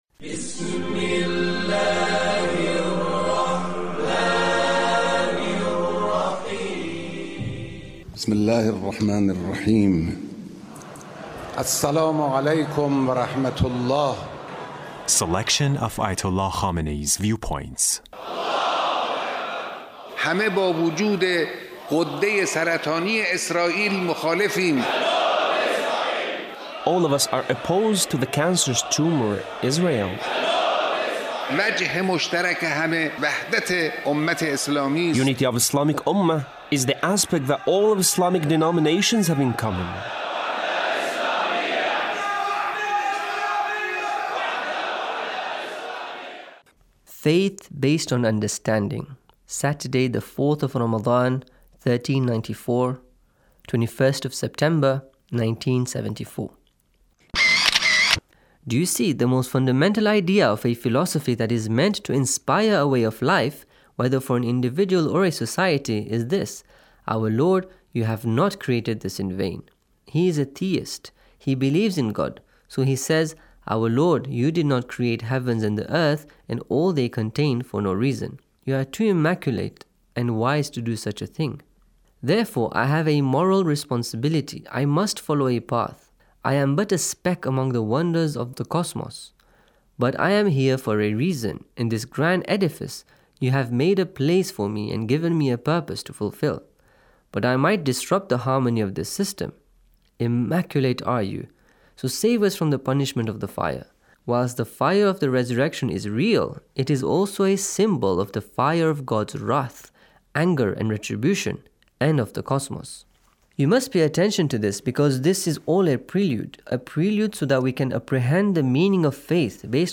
Leader's Speech on Taqwa